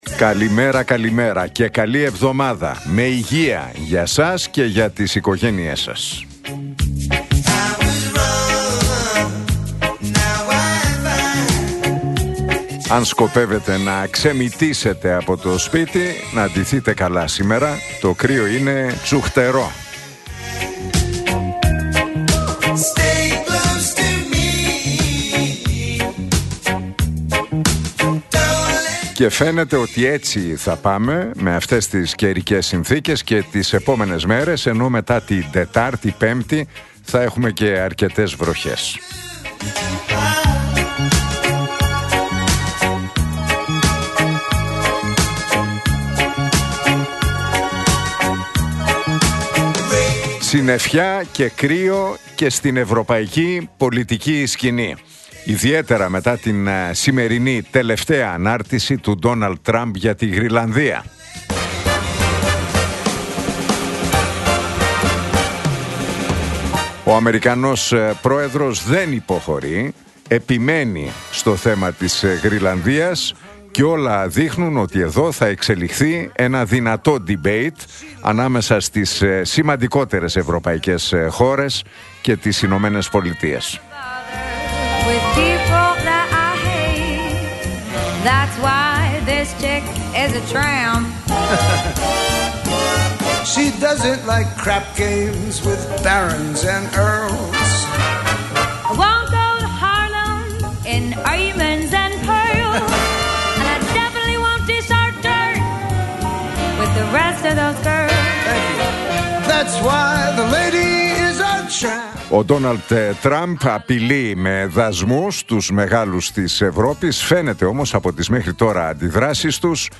Ακούστε το σχόλιο του Νίκου Χατζηνικολάου στον ραδιοφωνικό σταθμό Realfm 97,8, τη Δευτέρα 19 Ιανουαρίου 2026.